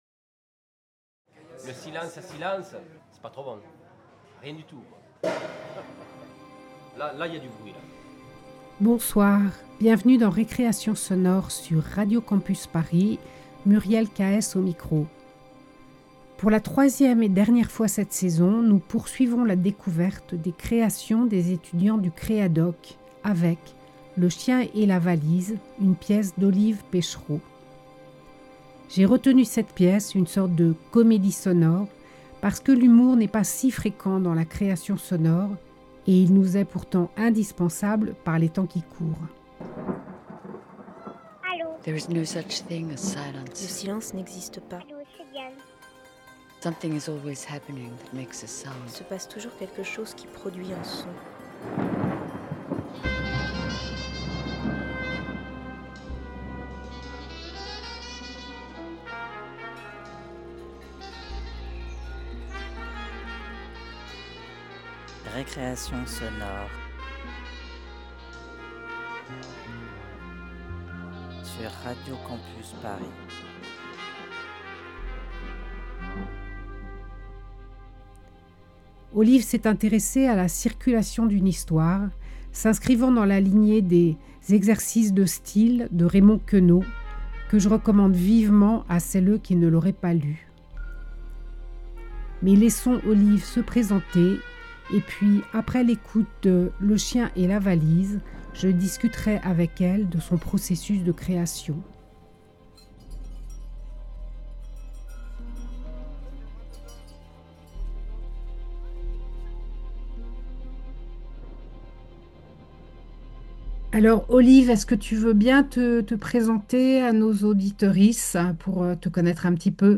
Pour une fois, car c'est assez rare, sauf peut être dans les fictions, je vous propose d'écouter un documentaire sonore de création qui s'inscrit résolument dans le champ de la comédie, de l'humour et du rire
Récit polyphonique, il nous propose une collection de versions d’une même blague, aussi différentes que les gens qui la transportent.